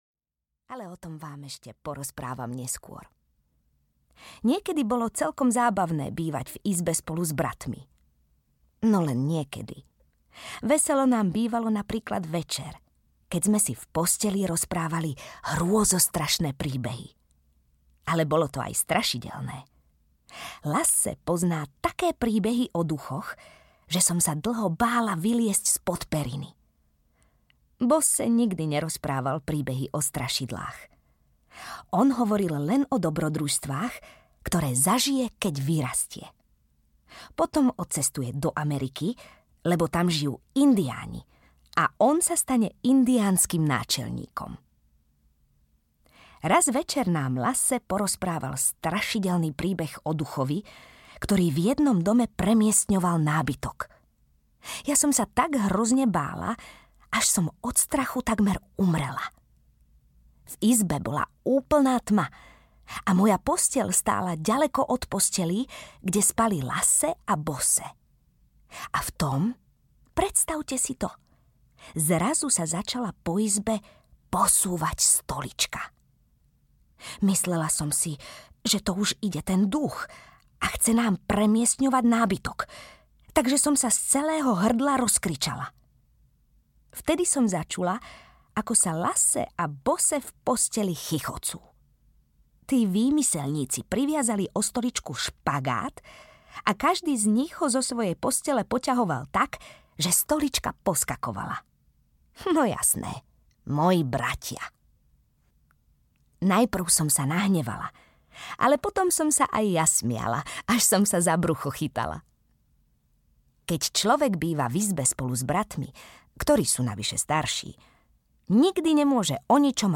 My všetky deti z Bullerbynu audiokniha
Ukázka z knihy
• InterpretTáňa Pauhofová